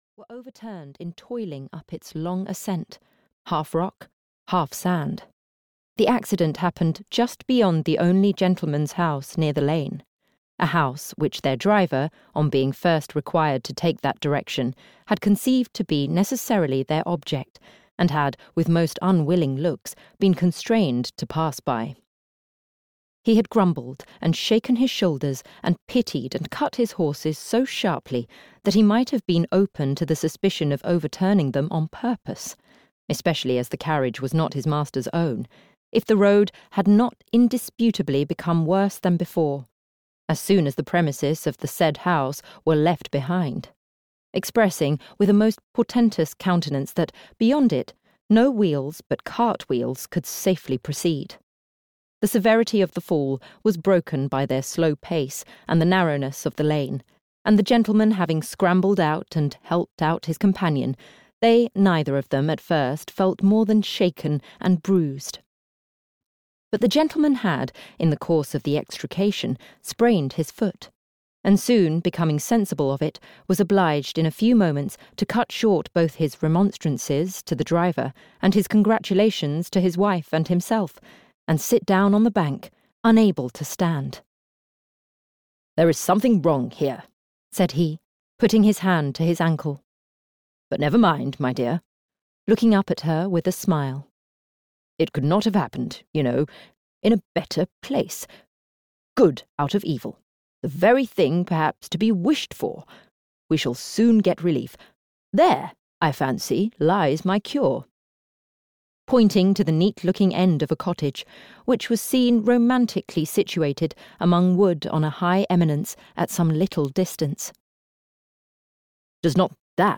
Sanditon and Other Stories (EN) audiokniha
Ukázka z knihy